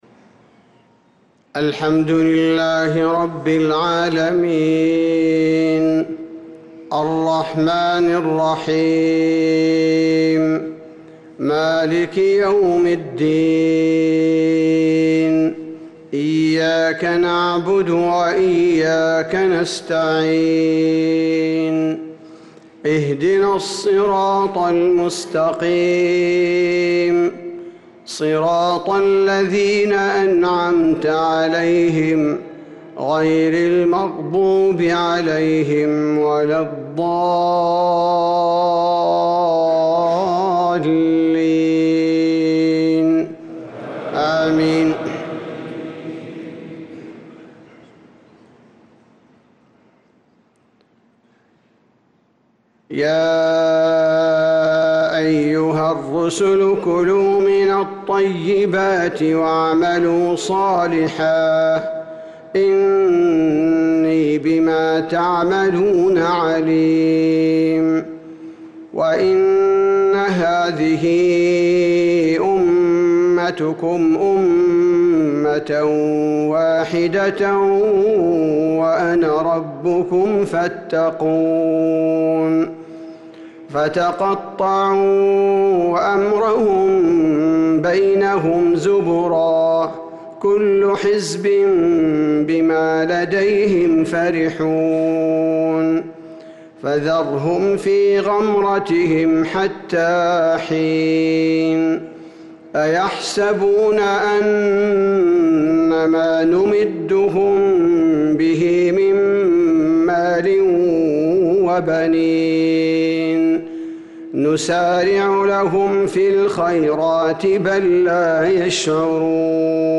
صلاة العشاء للقارئ عبدالباري الثبيتي 2 ذو القعدة 1445 هـ
تِلَاوَات الْحَرَمَيْن .